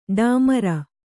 ♪ ḍāmara